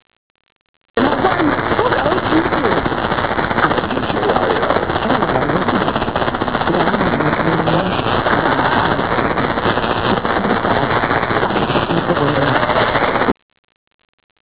This page contains DX Clips from the 2005 DX season!